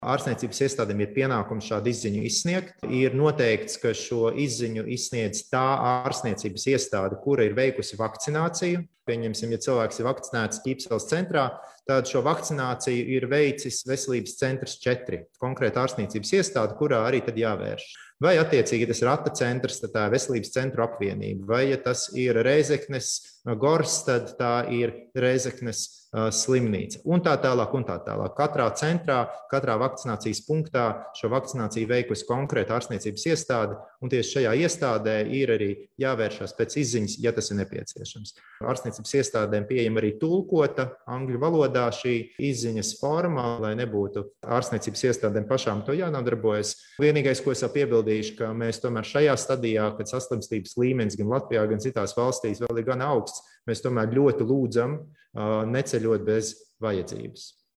Kamēr gaidām vienoto Eiropas digitālo Zaļo sertifkātu, kas ļaus pārvietoties arī pa citām Eiropas valstīm, Latvijā darbosies izziņu sistēma, kas apliecinās, ka persona ir saņēmusi visas imunitātes iegūšanai nepieciešamās  vakcīnas devas. Stāsta Veselības ministrs Daniels Pavļuts: